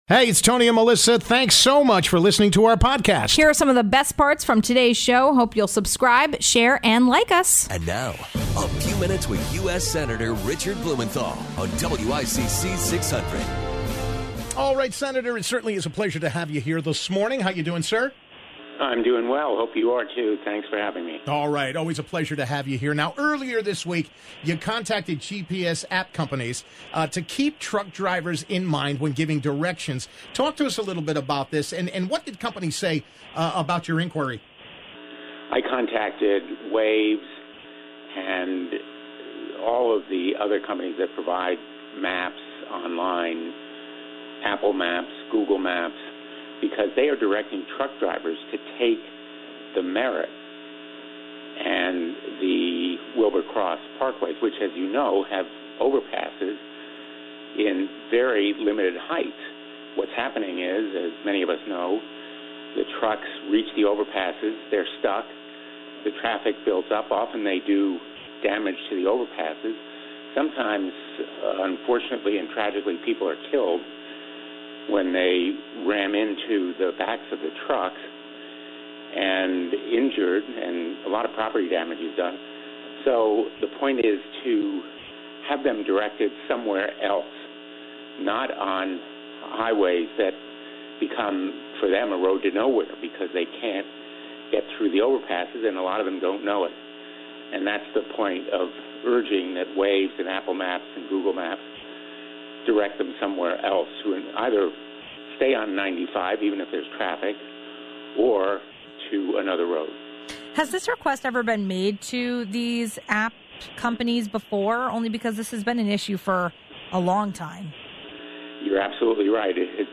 1. Sen. Blumenthal explains why it's crucial that GPS apps change their criteria.